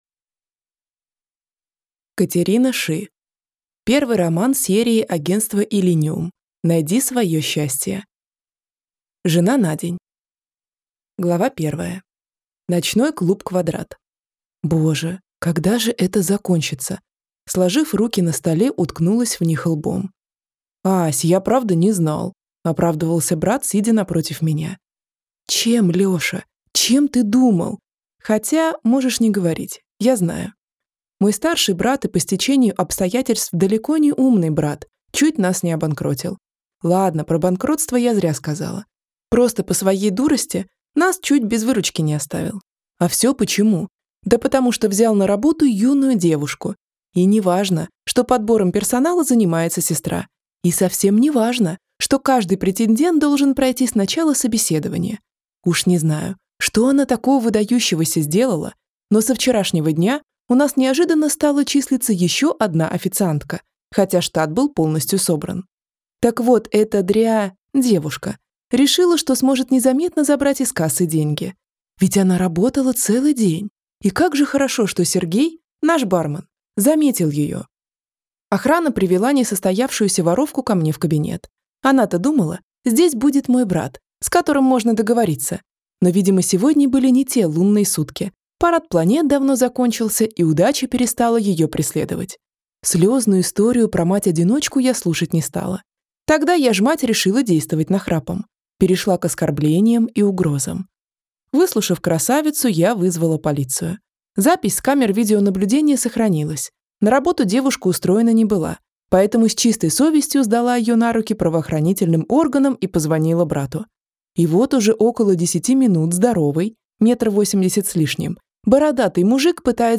Аудиокнига Жена на день | Библиотека аудиокниг
Прослушать и бесплатно скачать фрагмент аудиокниги